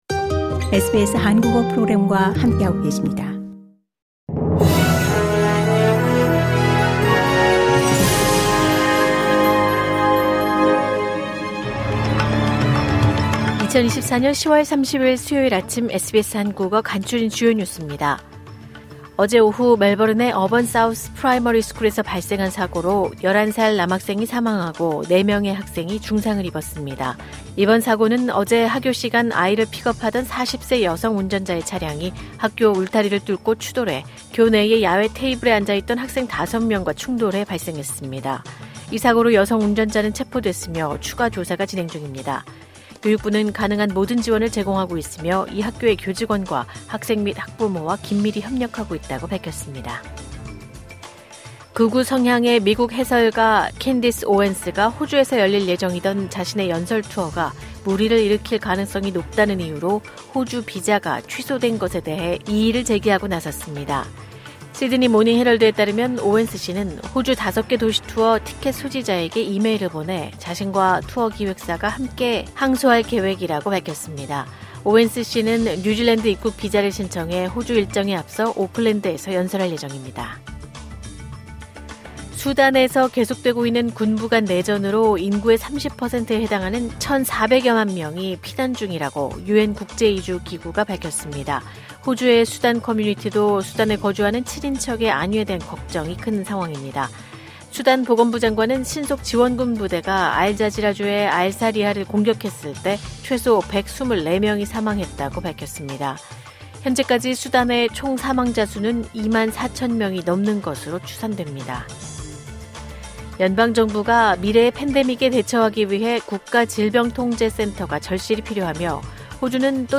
2024년 10월 30일 수요일 아침 SBS 한국어 간추린 주요 뉴스입니다.